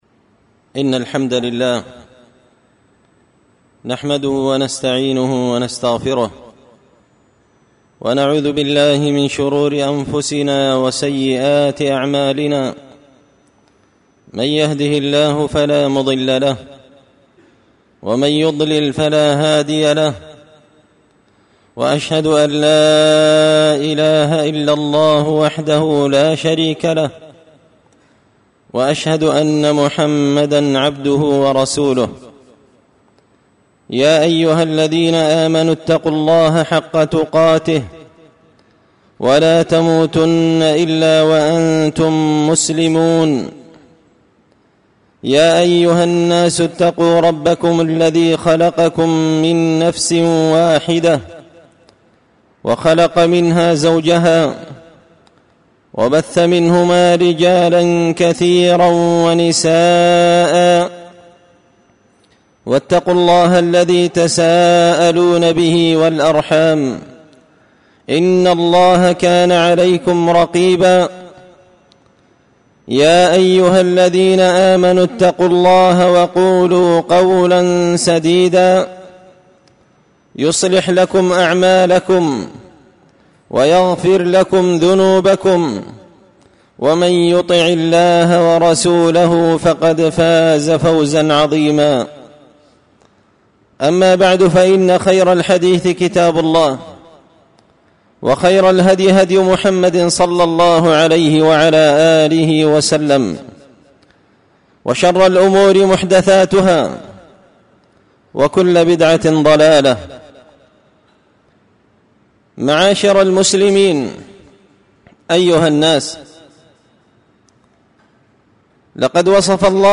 خطبة من مسجد الاستقامة بالمكلا
خطبة-مسجد-الاستقامة-بالمكلا.mp3